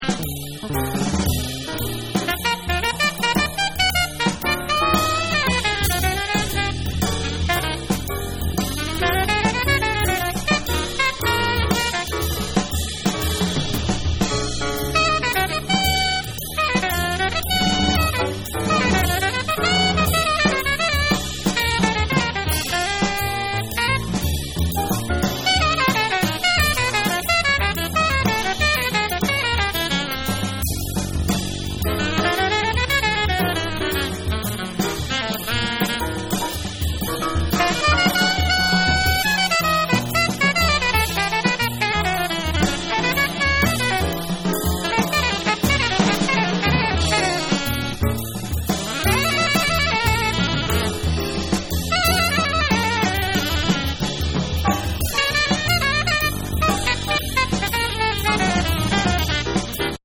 House, Soulful, Hip Hop, Reggae, Rock, Fusion, Jazz
A beautifully sensitive album
but overall the record is proudly acoustic at most moments.